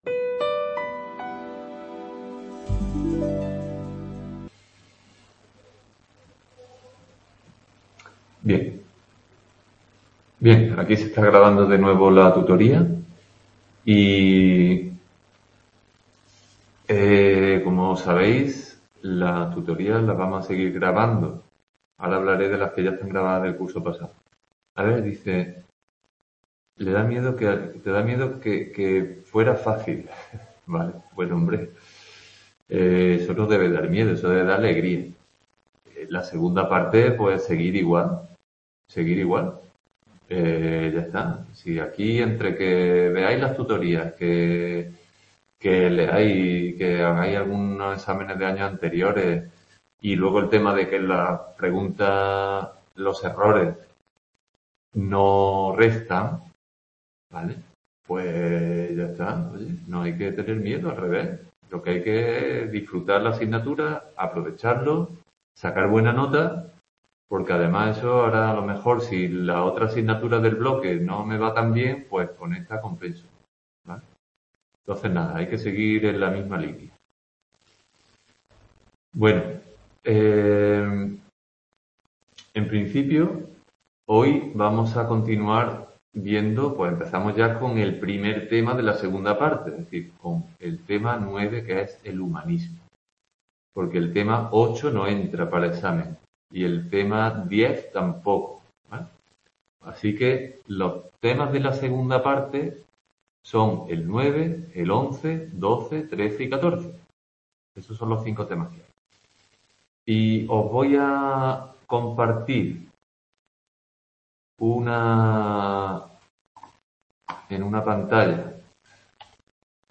Tutoría Psicología Tema 9. El humanismo (1) | Repositorio Digital